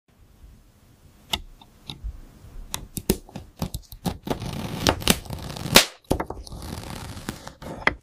part 13 | AI ASMR sound effects free download
part 13 | AI ASMR video for cutting DODGE Challenger car